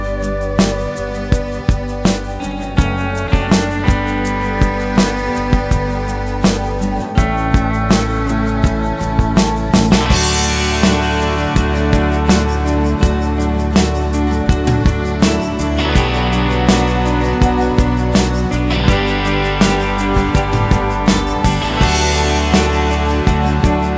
no Backing Vocals Ska 3:44 Buy £1.50